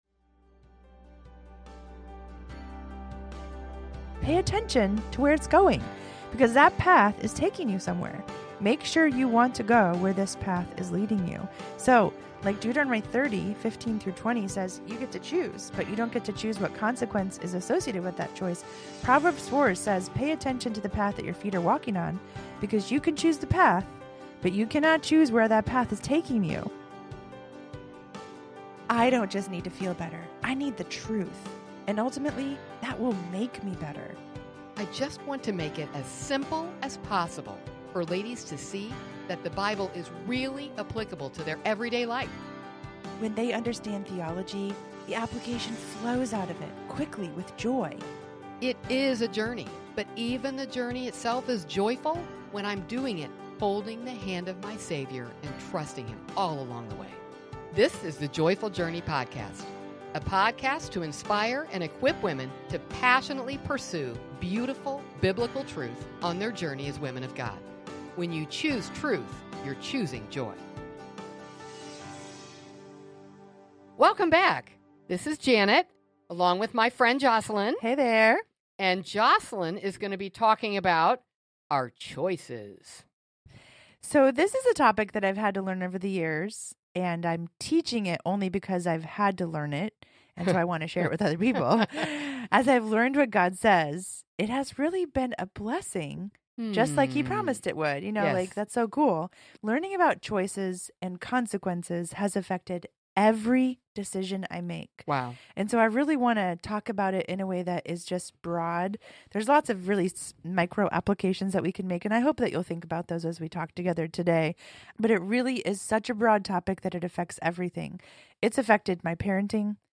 Through heartfelt conversation, they explore Scripture and reveal how God consistently expresses His desire to bless those who walk the path of righteousness. Visit the Joyful Journey website to sign up for our newsletter, view a transcript, and search previous episodes.